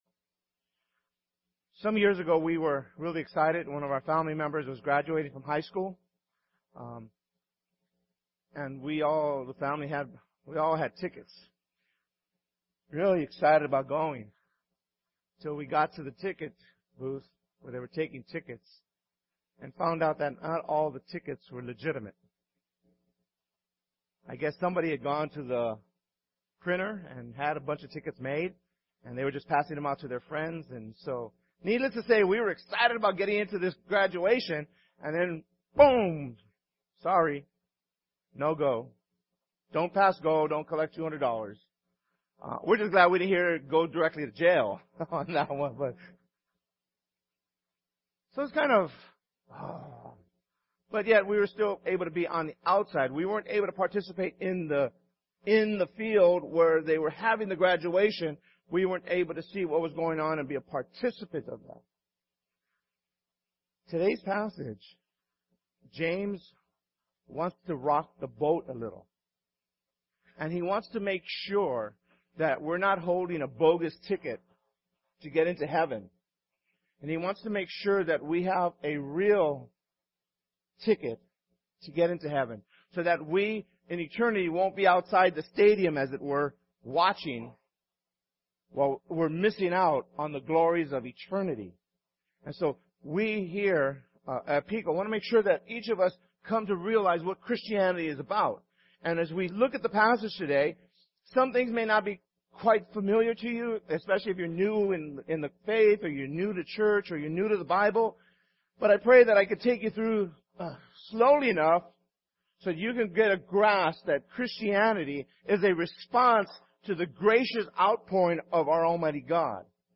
January 25th 2015 Sermon